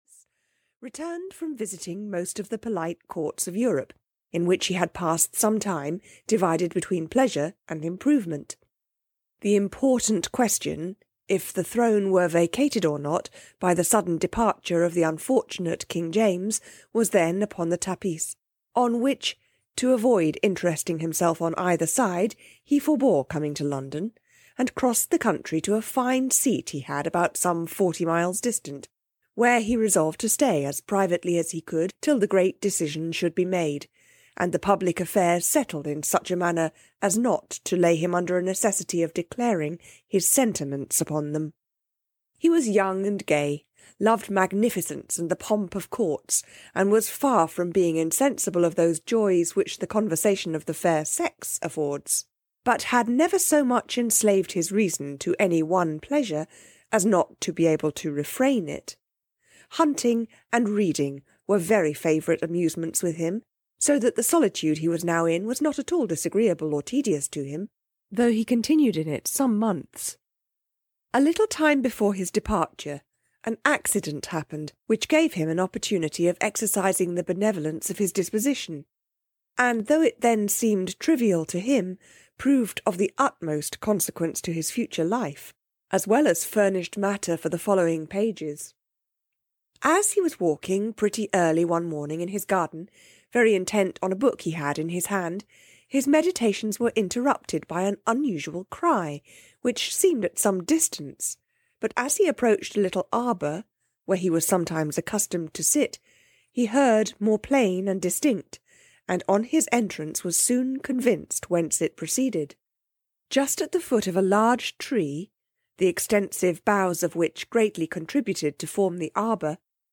The Fortunate Foundlings (EN) audiokniha
Ukázka z knihy